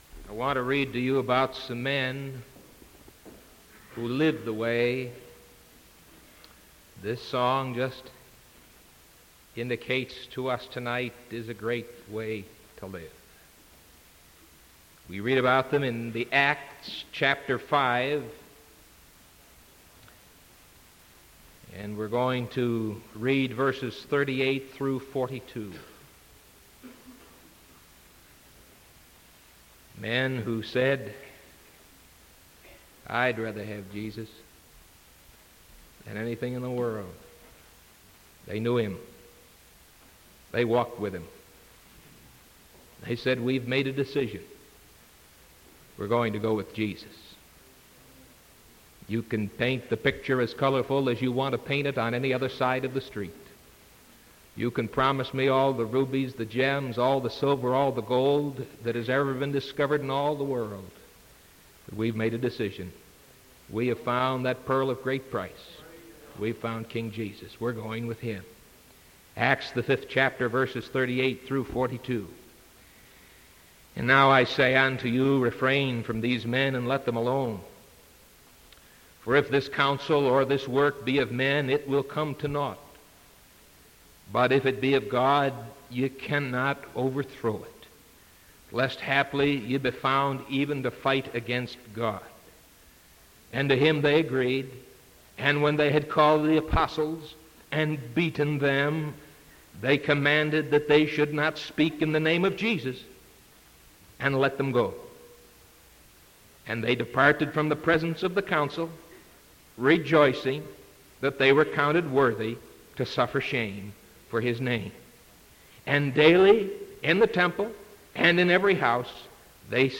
Sermon February 2nd 1975 PM